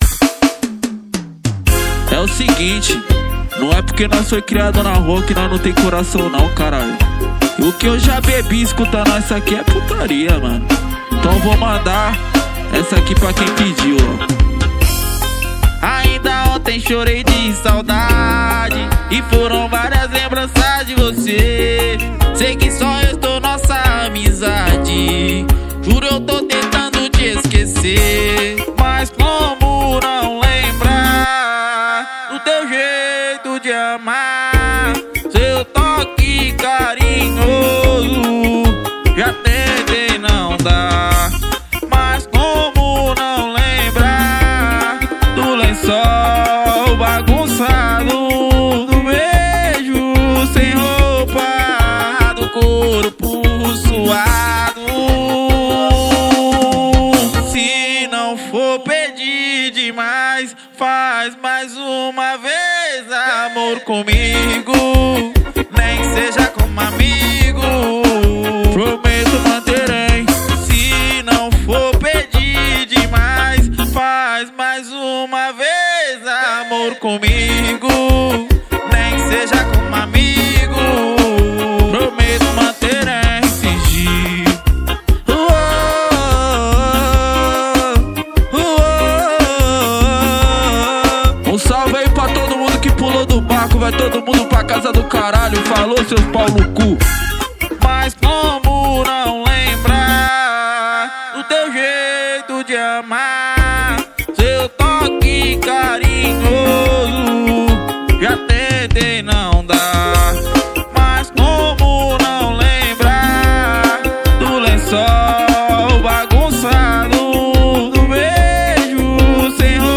2024-06-17 17:02:32 Gênero: Forró Views